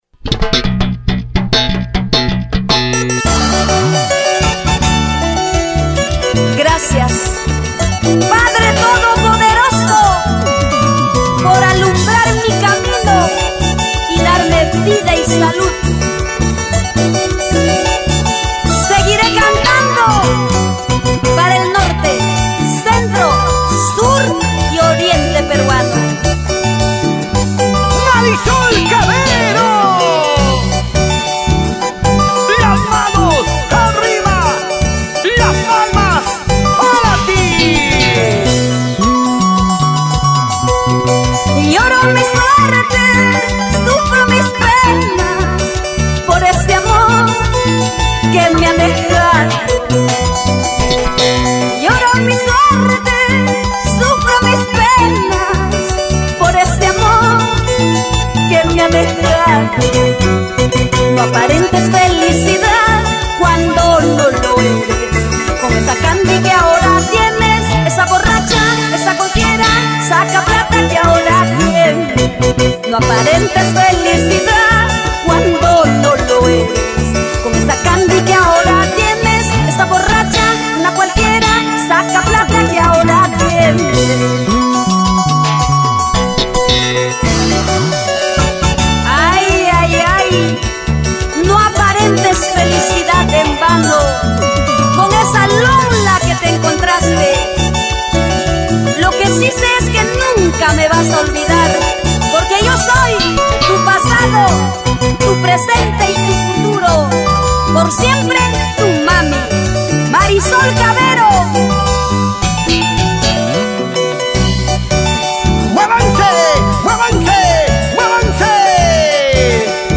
huayno